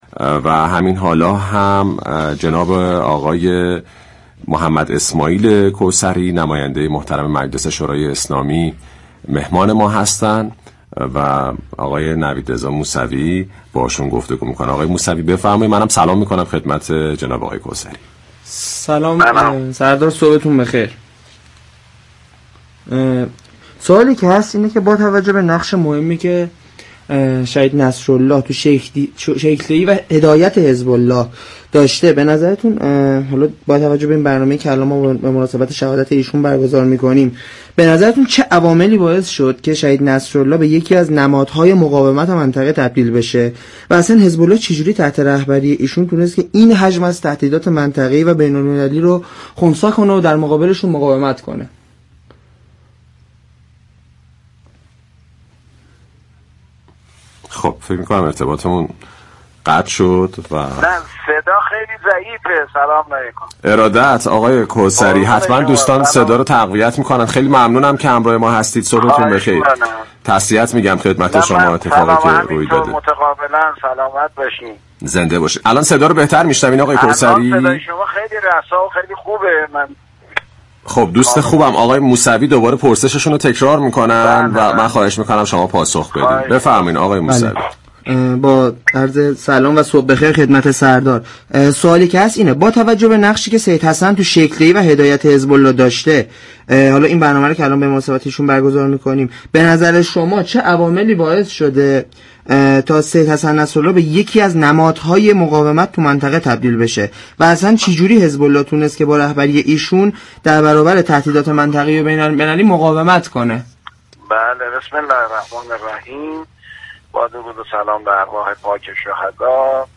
به گزارش پایگاه اطلاع رسانی رادیو تهران، محمداسماعیل كوثری عضو كمیسیون امنیت ملی سیاست خارجی مجلس شورای اسلامی در گفت و گو با ویژه برنامه «صبح تهران، داغ بیروت» ضمن تسلیت به مناسبت شهادت شهید سیدحسن نصرالله دبیركل شهید حزب‌الله لبنان، اظهار داشت: شهید نصرالله انسانی چند بعدی بود، از سویی طلبه و از سویی دیگر مجاهدی به تمام معنا و با اخلاق‌ترین فرد در مجموعه جبهه مقاومت بود.